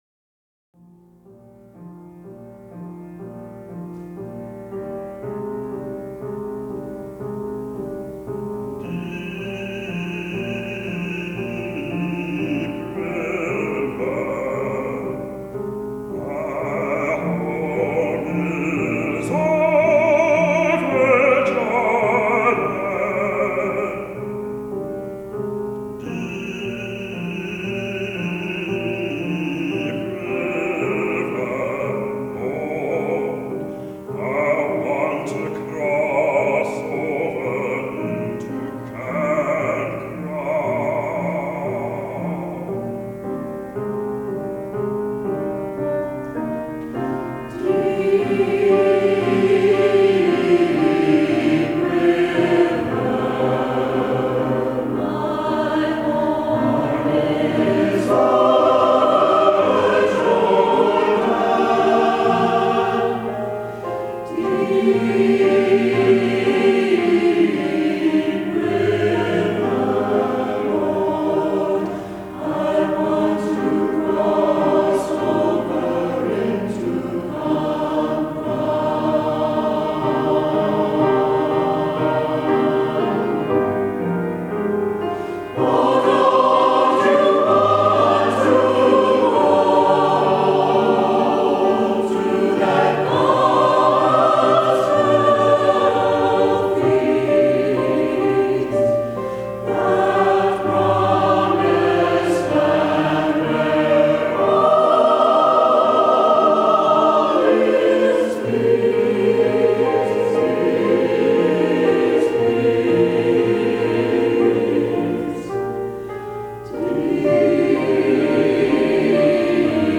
live recordings of various clients
Choir